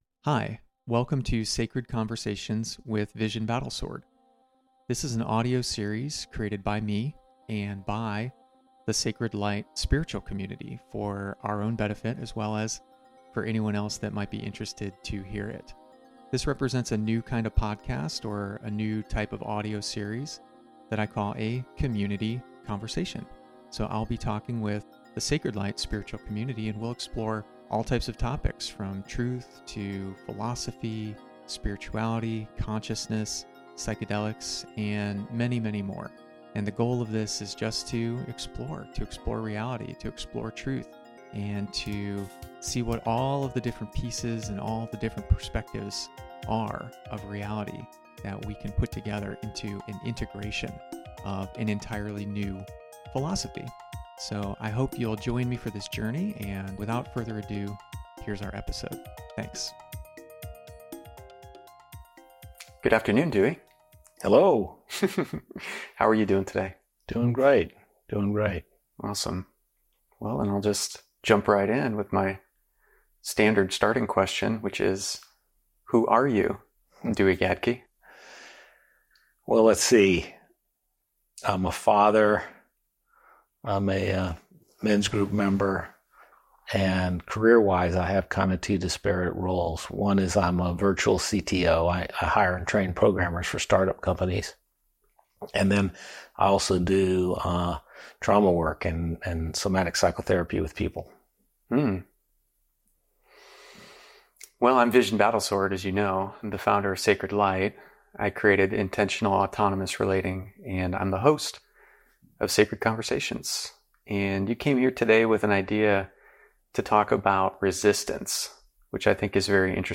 conversation15-resistance.mp3